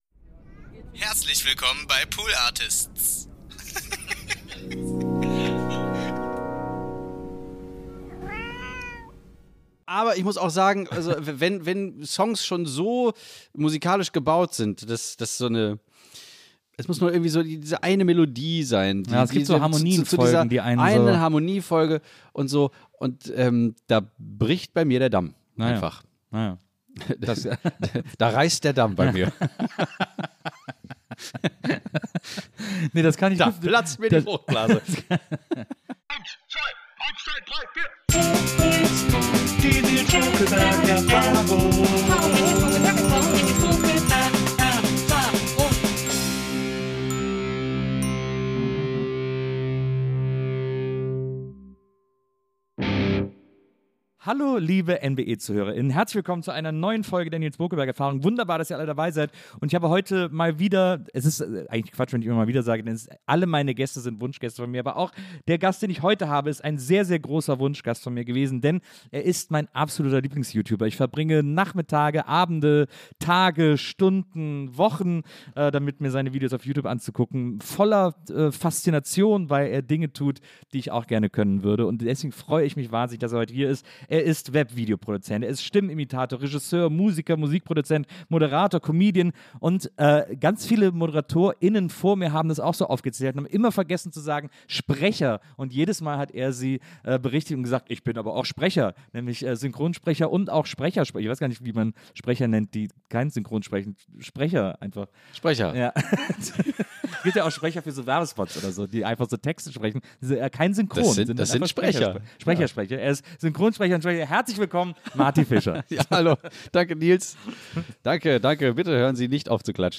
Herzlich Willkommen im Wohnzimmerstudio von Nilz Bokelberg!
Beide blicken gemeinsam auf eine aufregende NBE-Zeit voller spannender, schöner, trauriger, aber auch nachdenklicher und ernster Momente zurück. Nebenbei gibt es einige Überraschungen und vor allem viele Sprachnachrichten von ehemaligen Gäst:innen und NBE-Teammitgliedern zu hören.